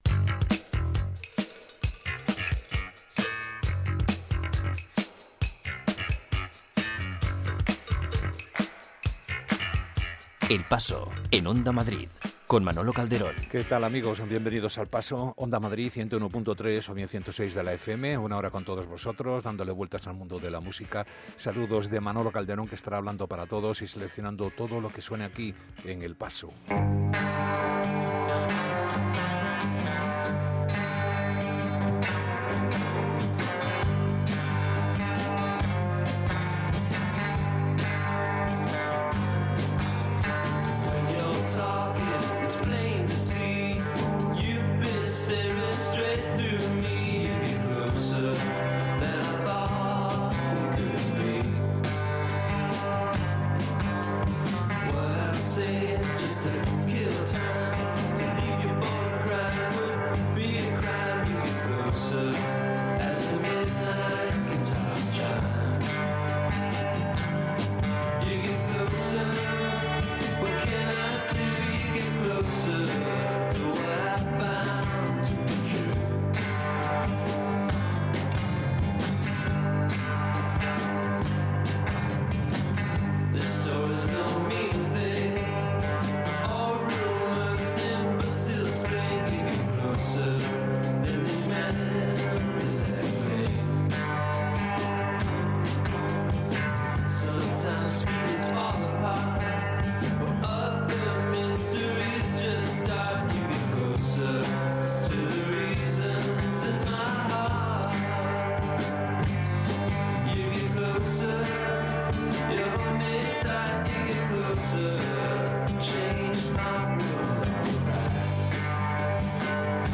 No hay década mala en el repaso musical que hace El Paso, desde el primigenio rock de los años 50 hasta el blues contemporáneo, pasando por el beat, power pop, surf, punk, rock o música negra.